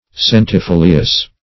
Search Result for " centifolious" : The Collaborative International Dictionary of English v.0.48: Centifolious \Cen`ti*fo"li*ous\, a. [L. centifolius; centum + folium leaf.] Having a hundred leaves.